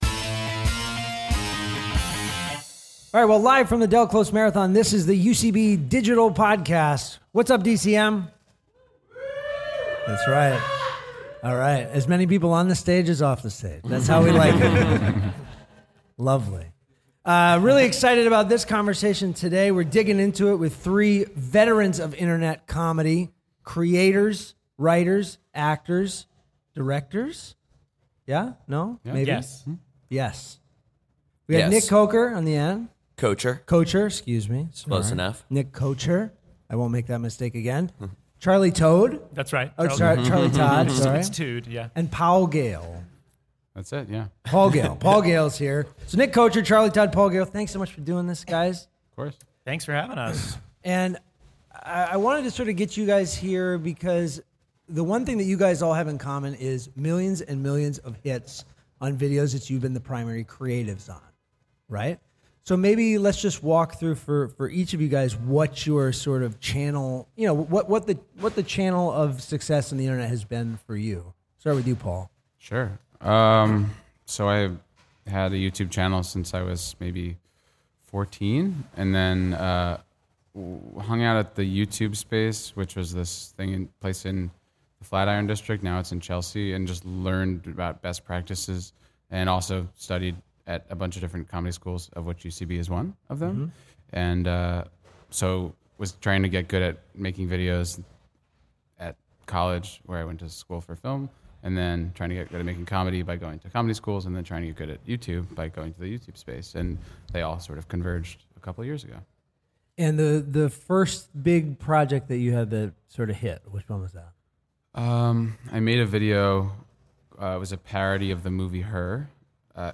Recorded live in New York City during the 18th annual Del Close Marathon.